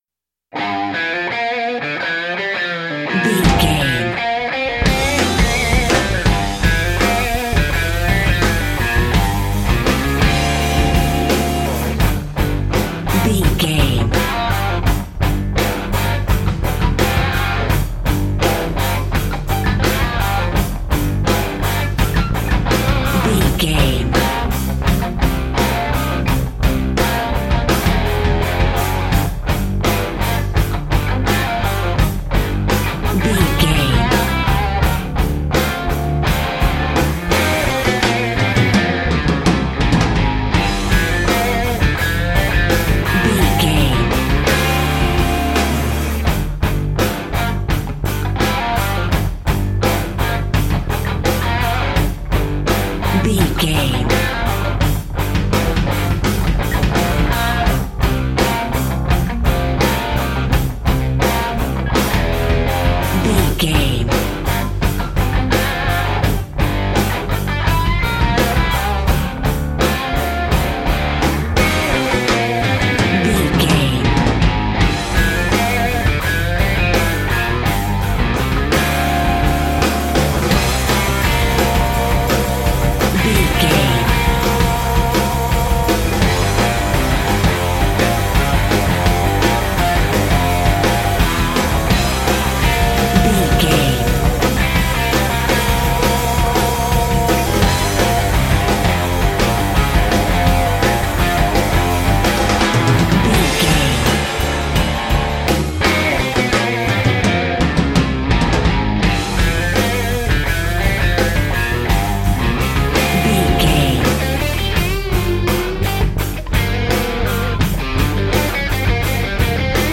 Ionian/Major
drums
electric guitar
bass guitar
pop rock
hard rock
lead guitar
aggressive
energetic